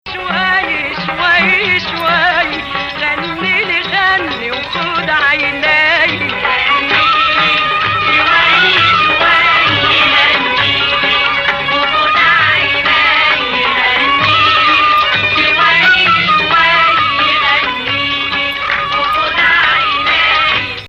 Rast 1